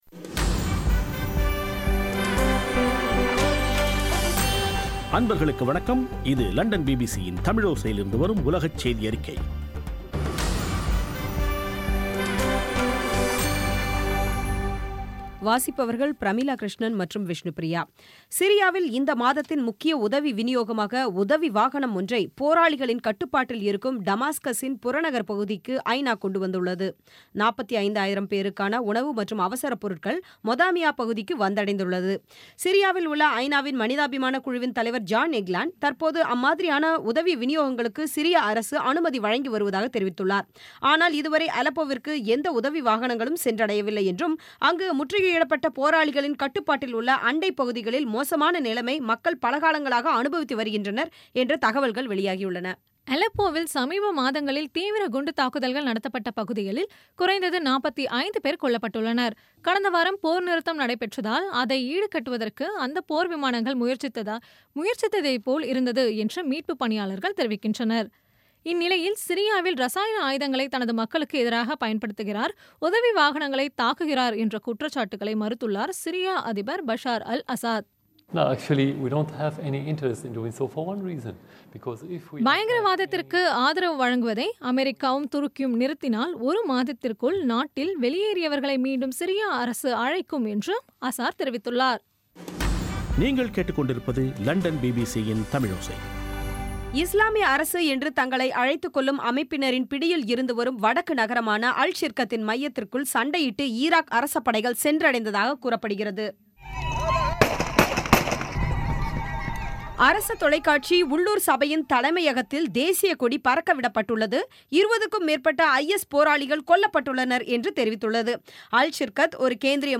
பி பி சி தமிழோசை செய்தியறிக்கை (22/09/2016)